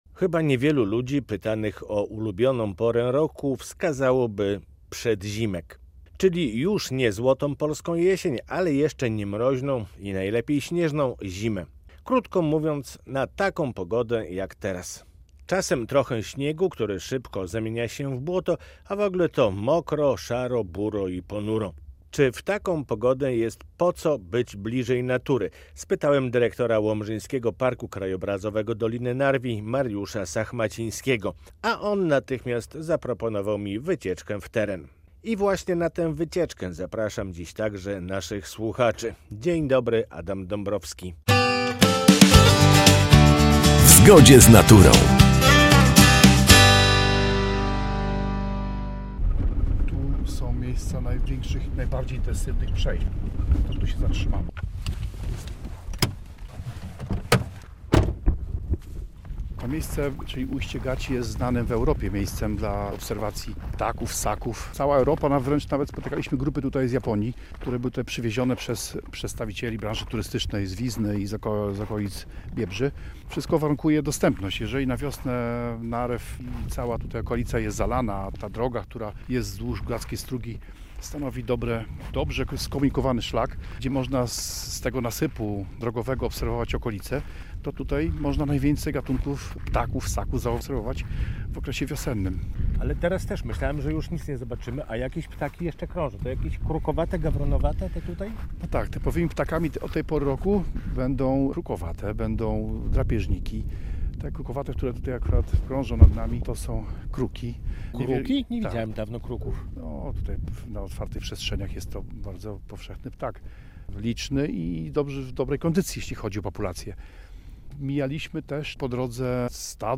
który zabrał naszego dziennikarza na wyprawę terenową do tegoż parku.